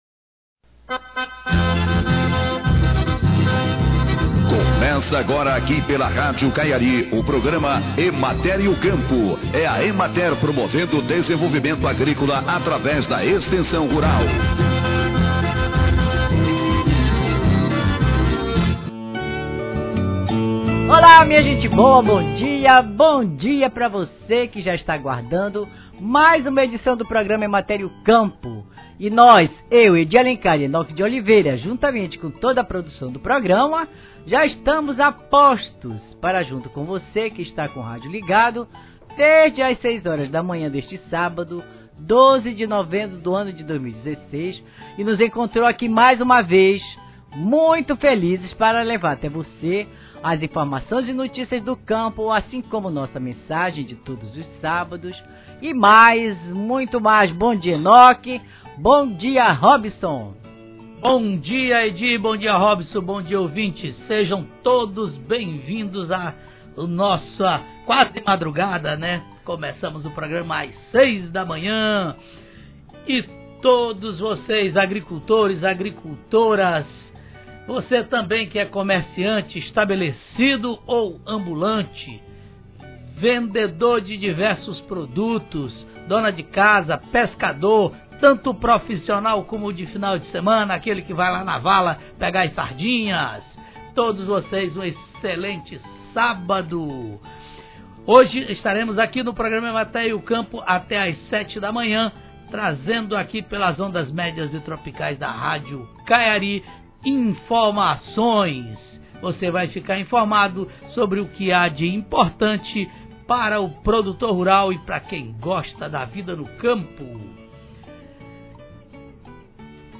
Programa de Rádio